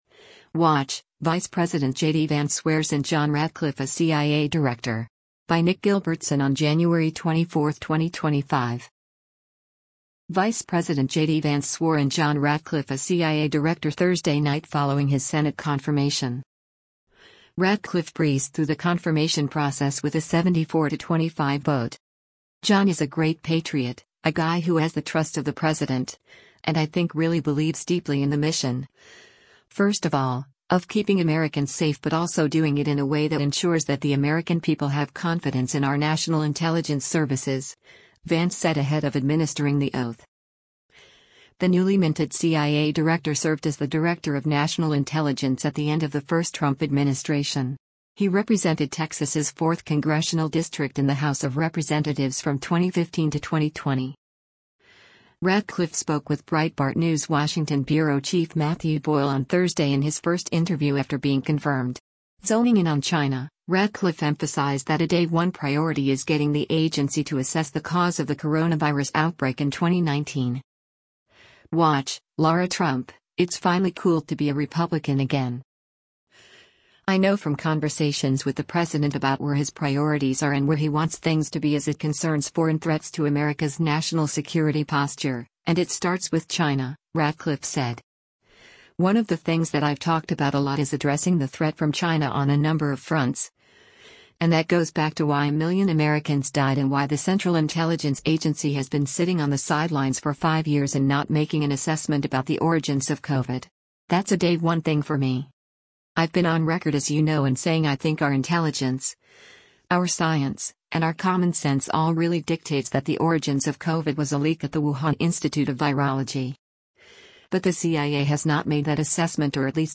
WATCH: Vice President JD Vance Swears in John Ratcliffe as CIA Director
Vice President JD Vance swore in John Ratcliffe as CIA director Thursday night following his Senate confirmation.